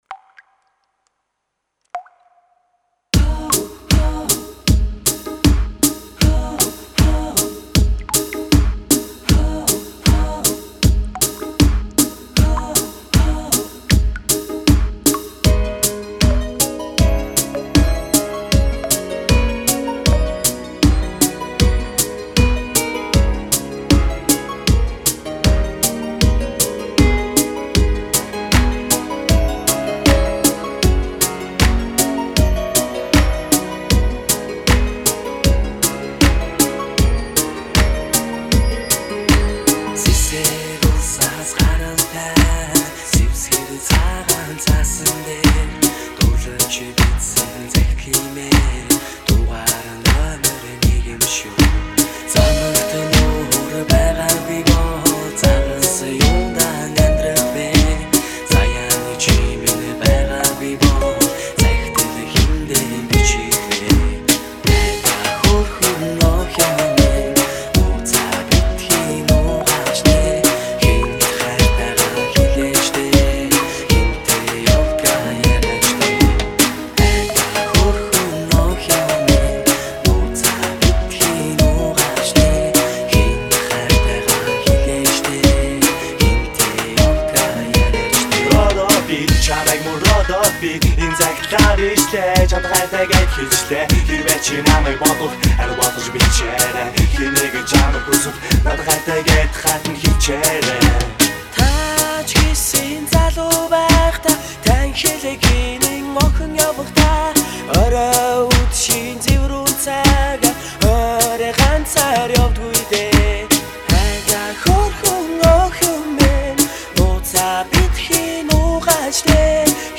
100集广场舞专辑，每张专辑都是从新配器，适合广场舞。
【形  式】广场舞专用
三步踩
感谢楼主提供，节奏真好听。